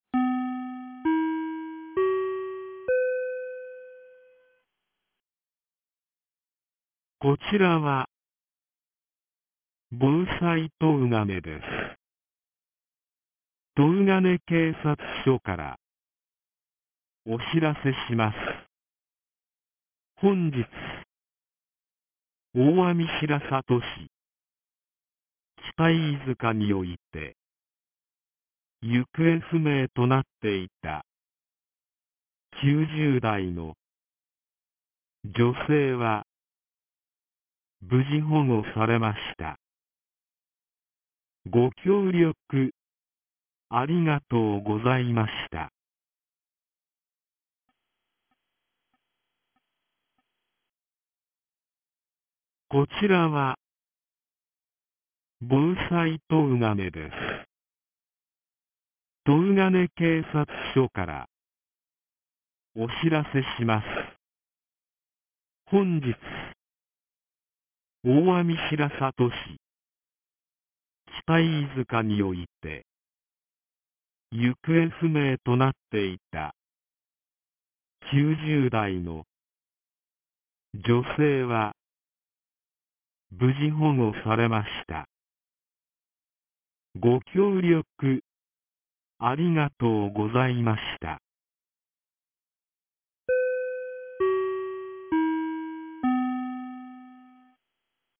2025年04月15日 18時02分に、東金市より防災行政無線の放送を行いました。